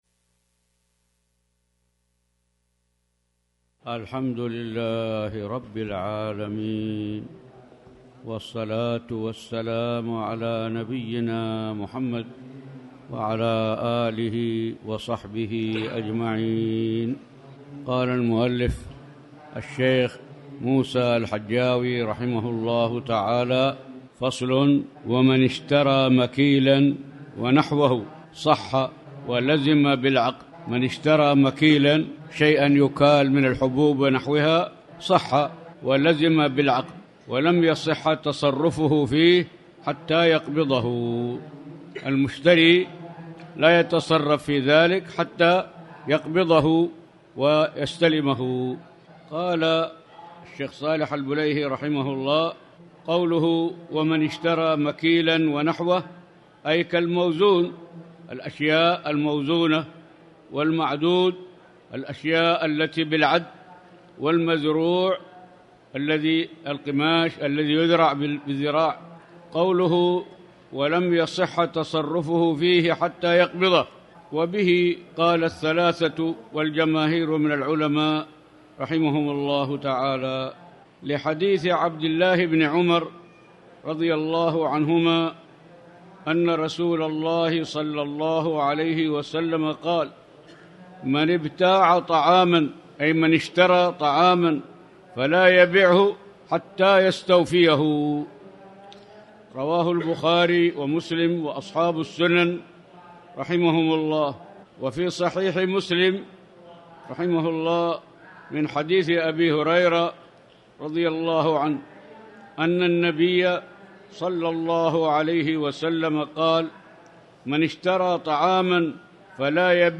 تاريخ النشر ١٨ صفر ١٤٣٩ هـ المكان: المسجد الحرام الشيخ